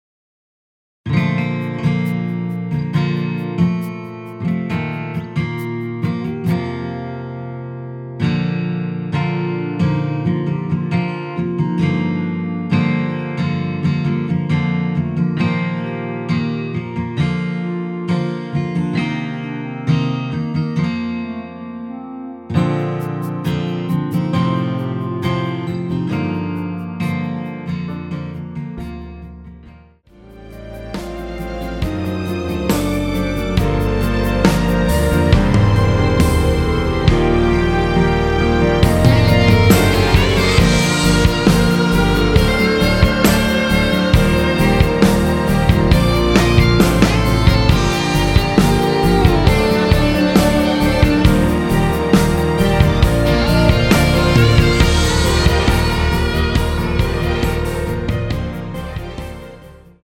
원키에서(-3)내린 (1절+후렴) 멜로디 포함된 MR입니다.(미리듣기 확인)
Bb
앞부분30초, 뒷부분30초씩 편집해서 올려 드리고 있습니다.
중간에 음이 끈어지고 다시 나오는 이유는